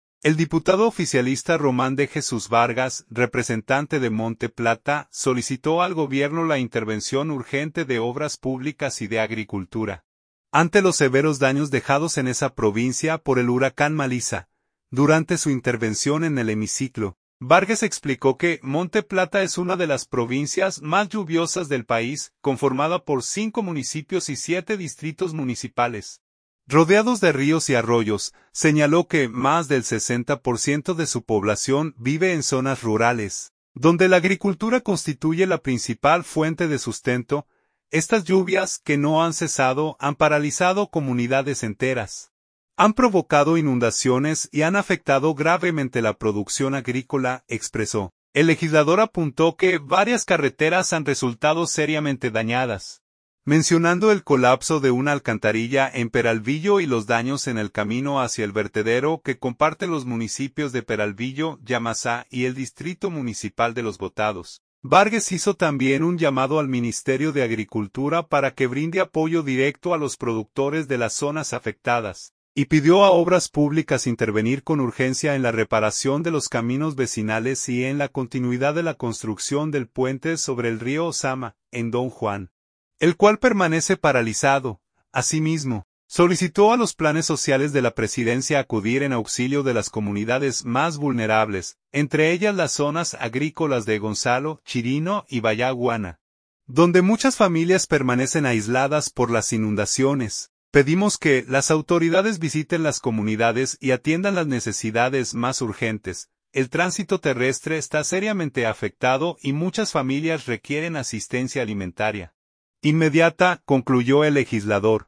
Durante su intervención en el hemiciclo, Vargas explicó que Monte Plata es una de las provincias más lluviosas del país, conformada por cinco municipios y siete distritos municipales, rodeados de ríos y arroyos.